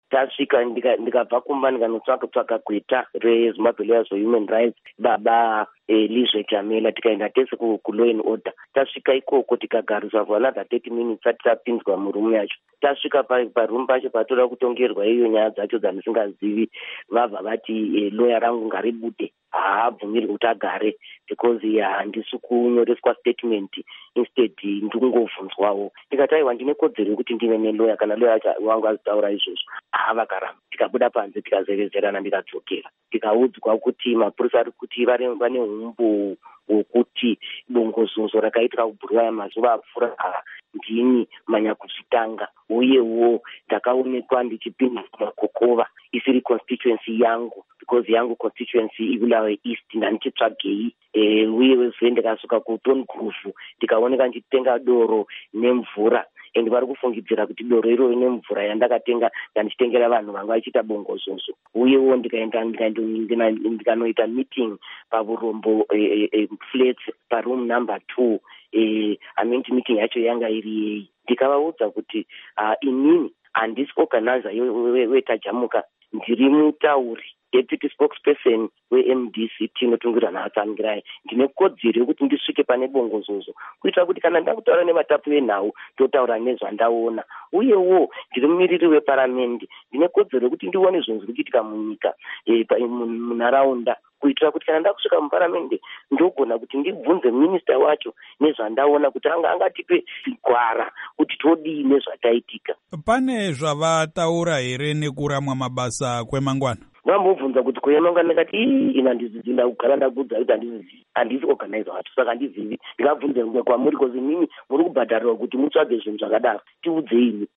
Hurukuro naMuzvare Thabitha Khumalo